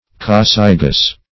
coccygeous - definition of coccygeous - synonyms, pronunciation, spelling from Free Dictionary
Coccygeous \Coc*cyg"e*ous\, a.